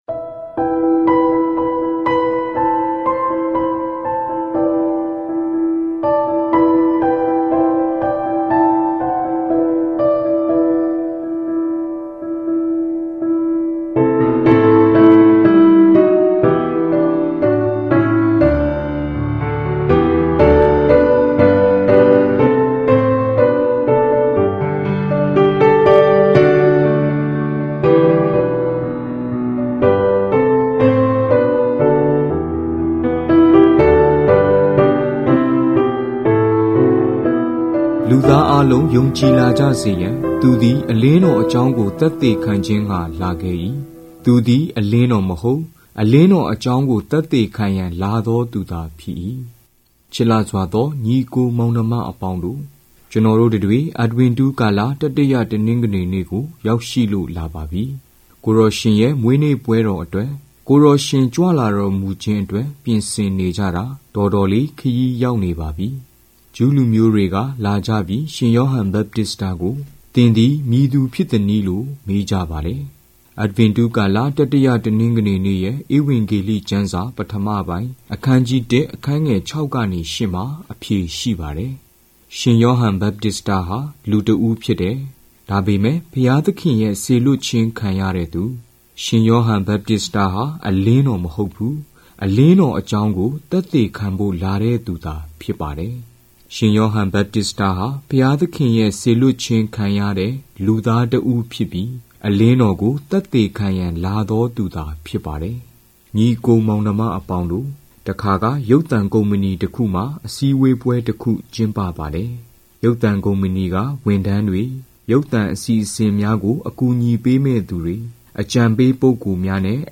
Directory Listing of mp3files/Myanmar/Audio Programs/Homilies/ (Myanmar Archive)